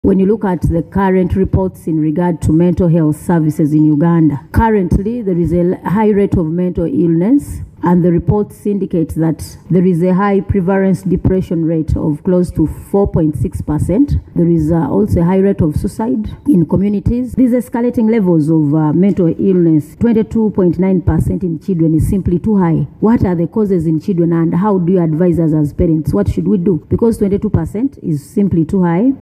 Hon. Gorreth Namugga, the Chairperson of the Committee noted that there is a high prevalence depression rate of close to 4.6 per cent, adding that the suicide rates in communities have also increased.